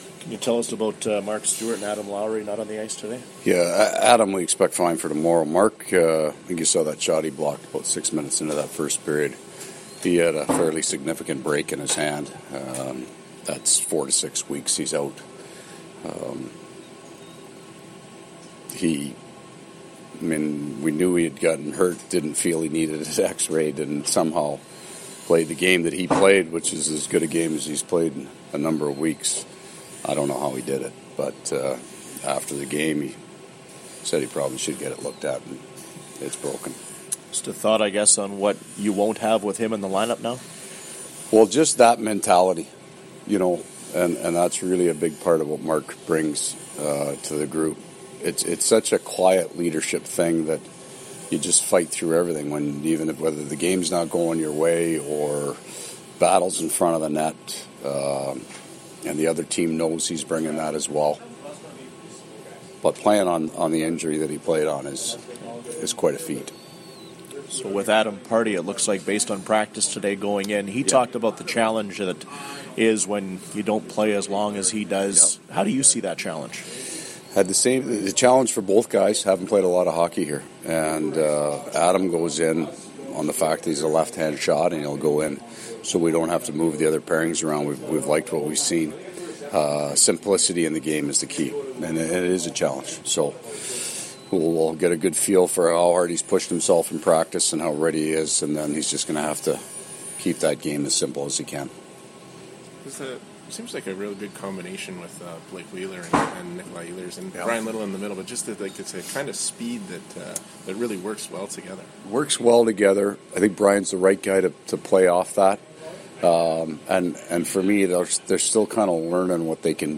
Coach scrum
Coach Maurice chatted with media following the skate at PNC Arena.